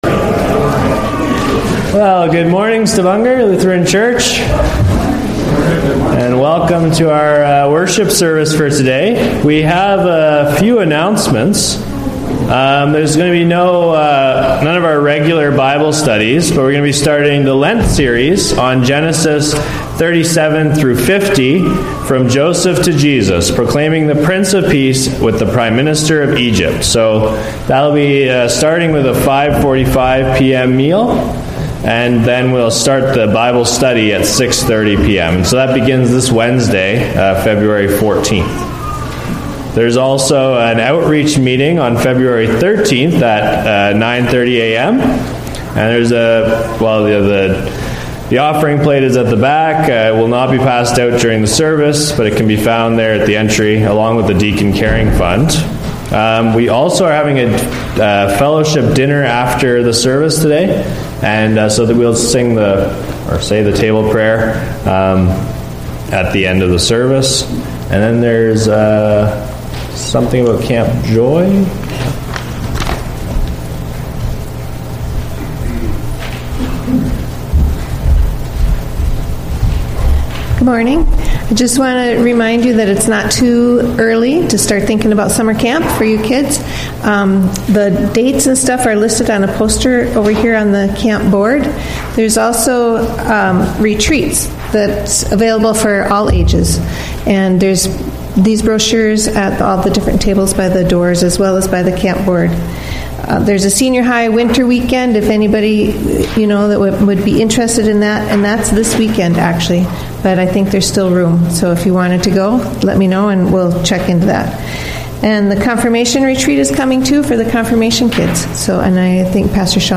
Sermons - Stavanger Lutheran Church
From Series: "Sunday Worship"